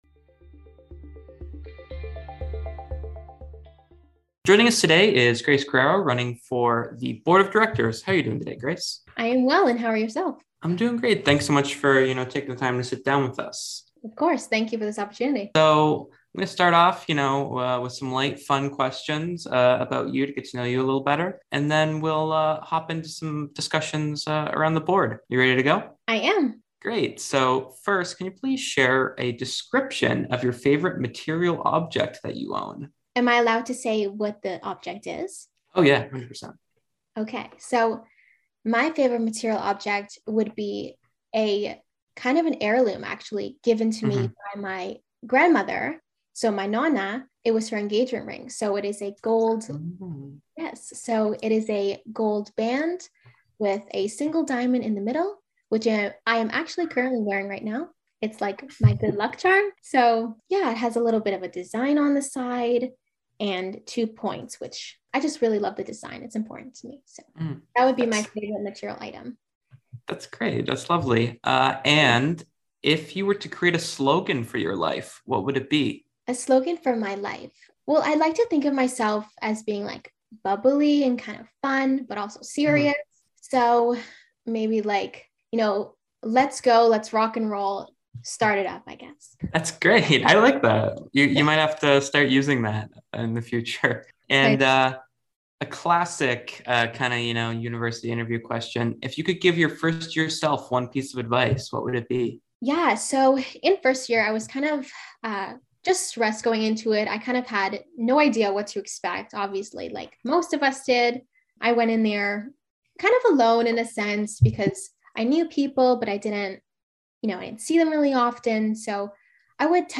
Candidate Interview